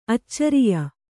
♪ accariya